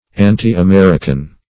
Anti-American \An`ti-A*mer"i*can\, a.